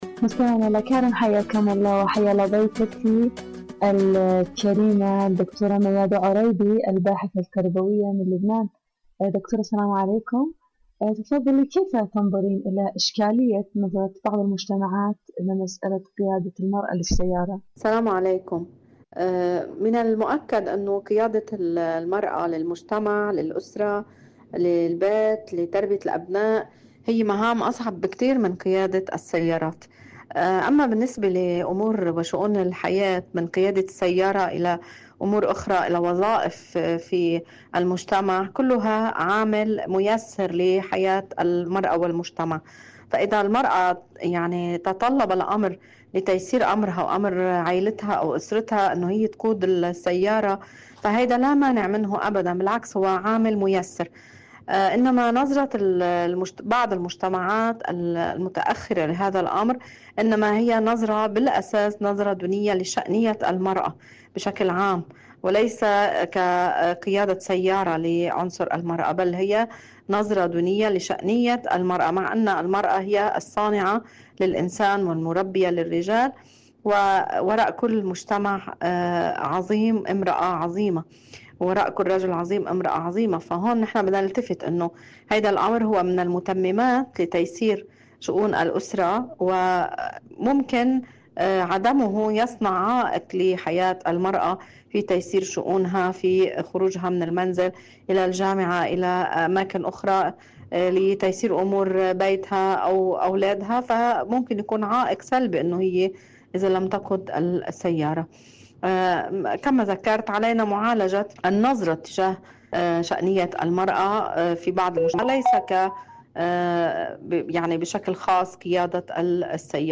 إذاعة طهران- عالم المرأة: مقابلة إذاعية